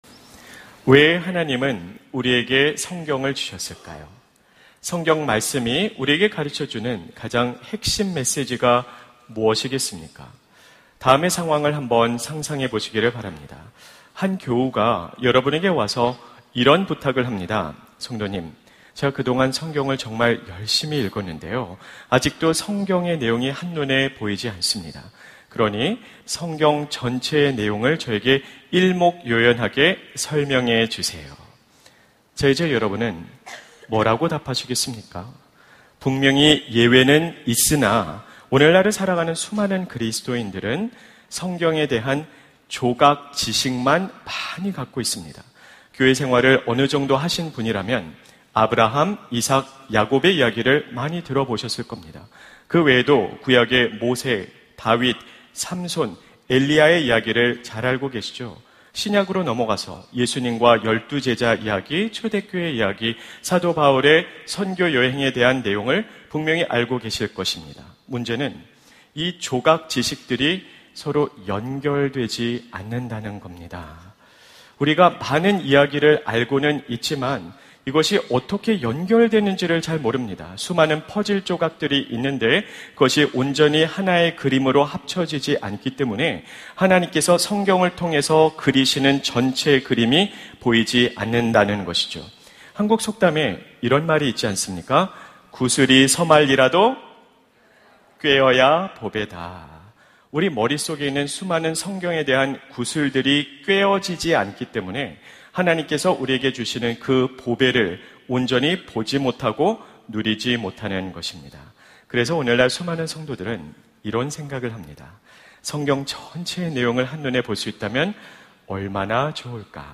설교 : 주일예배 복음수업 - 복음수업 4 : 성경 전체를 요약해 드립니다!